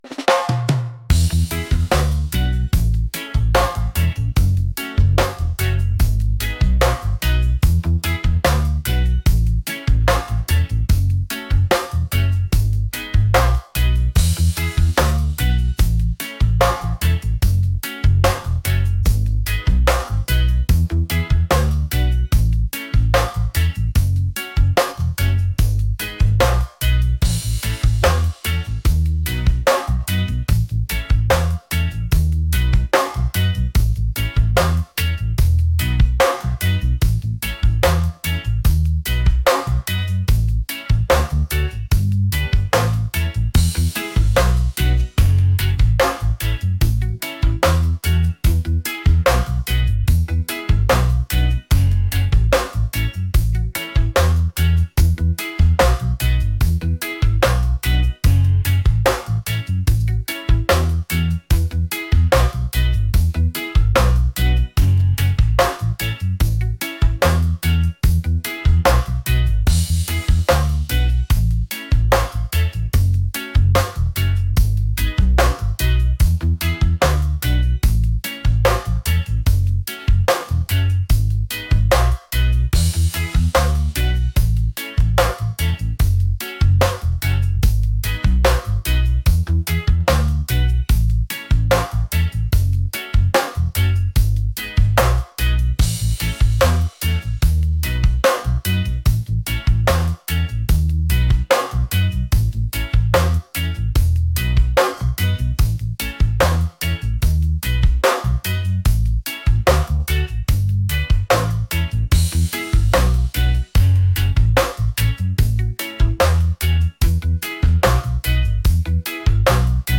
upbeat | reggae | laid-back